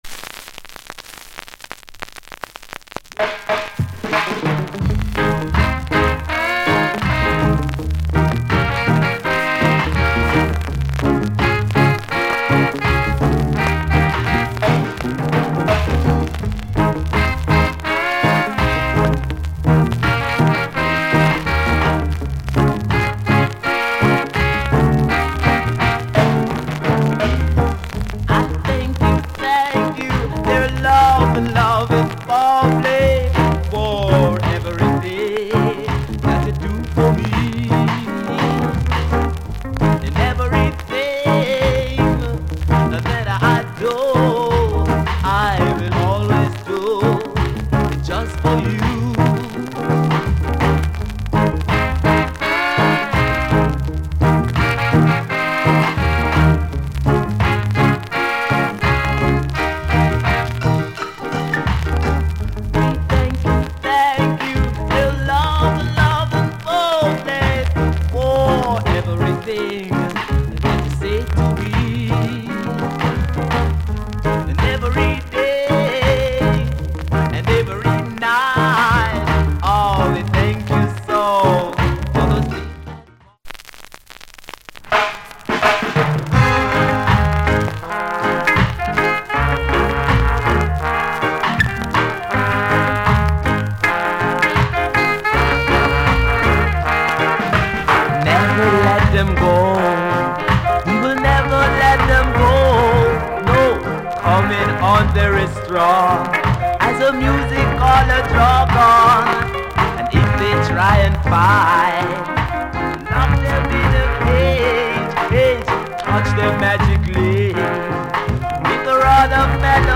Male Vocal Condition VG Soundclip